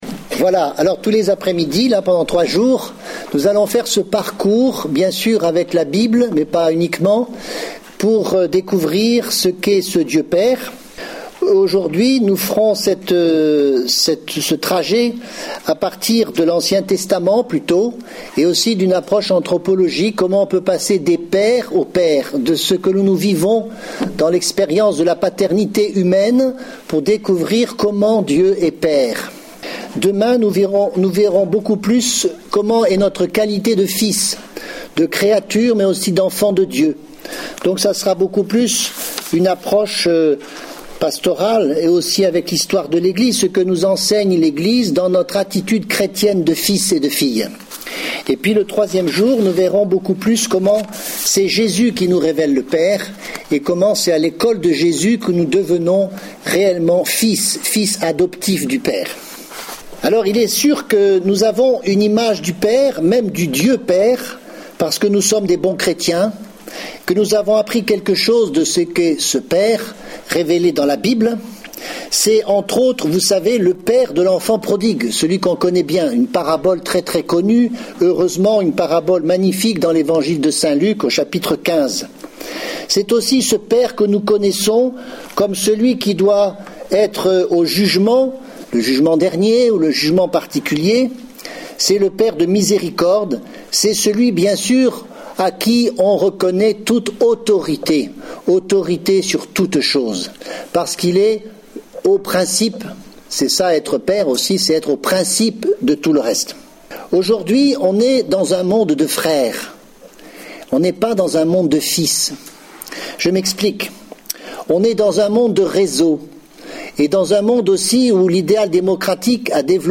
Enseignement
Enregistré en 2011 à Lisieux (Session des Béatitudes du 1er au 5 août 2011)